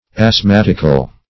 Search Result for " asthmatical" : The Collaborative International Dictionary of English v.0.48: Asthmatic \Asth*mat"ic\, Asthmatical \Asth*mat"ic*al\, a. [L. asthmaticus, Gr.